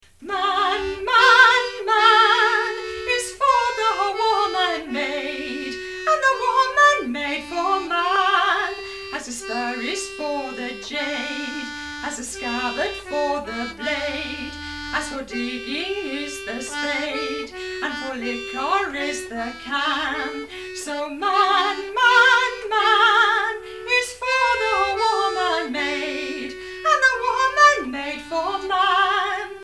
accordion and drum
a brief sample of one of the songs.